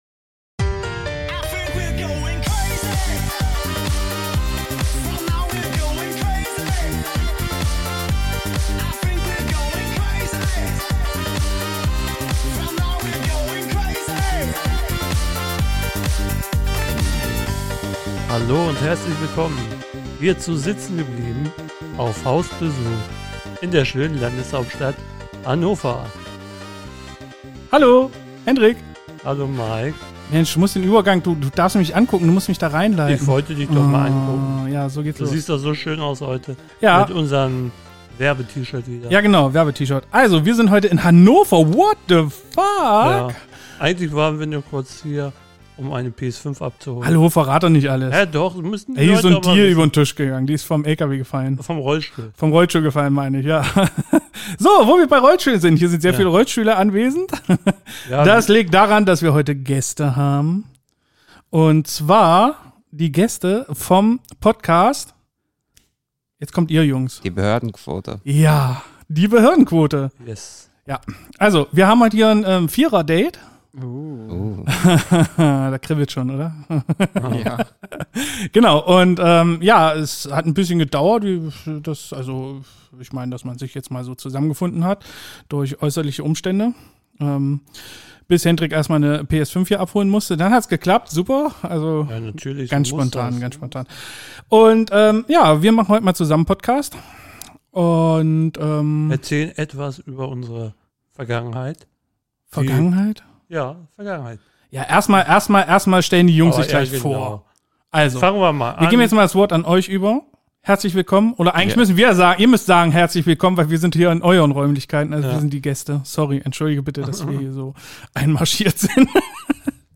Und zwar unsere erste Folge zusammen mit den Jungs vom Die BEHÖRDENQUOTE Podcast aus Hannover.
Drei Rollifahrer und ein Minderheiten-Fußgänger in einer Folge!